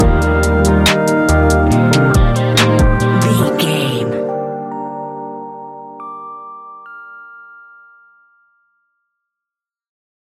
Ionian/Major
A♯
chilled
laid back
Lounge
sparse
new age
chilled electronica
ambient
atmospheric
instrumentals